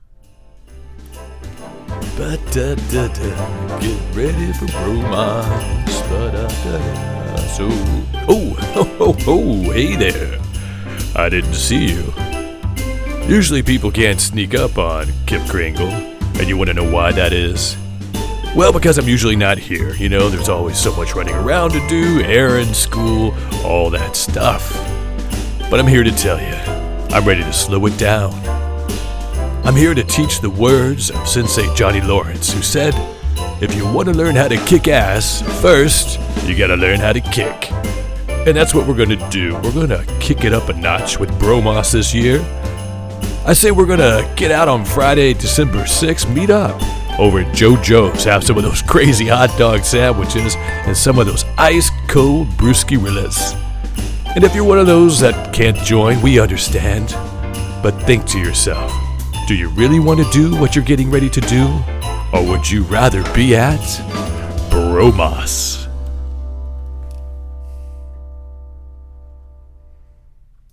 Middle Aged (35-54)
Male
Audio invitation
Fun Enjoyable Character
Radio Host